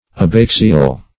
Abaxial \Ab*ax"i*al\ ([a^]b*[a^]ks"[i^]*al), Abaxile \Ab*ax"ile\